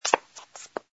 sfx_fturn_female02.wav